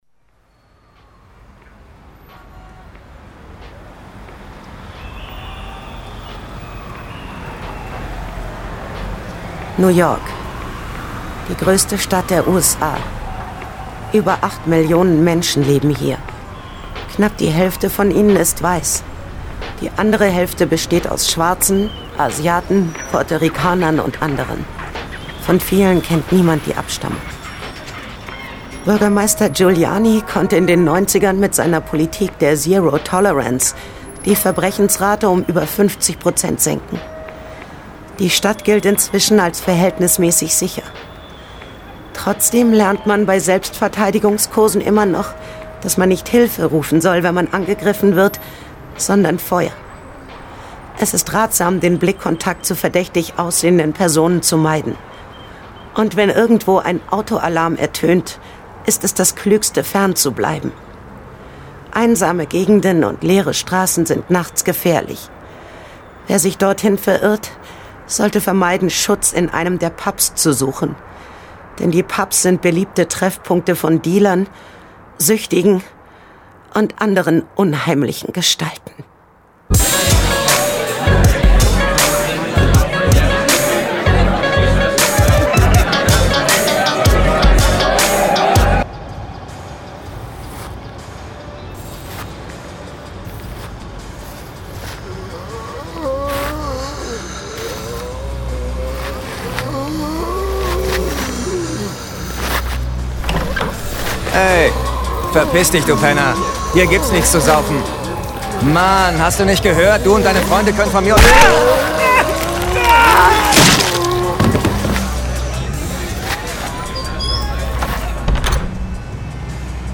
John Sinclair - Folge 50 Zombies in Manhattan. Hörspiel.